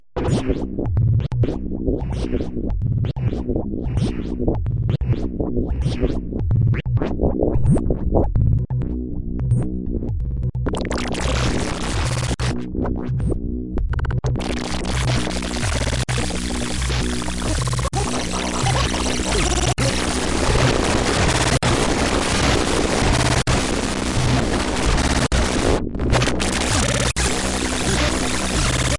Abstract Glitch Effects » Abstract Glitch Effects 008
描述：Abstract Glitch Effects
标签： Scifi Weird SoundDesign Effects Design Glitch Random Abstract Electric Sound
声道立体声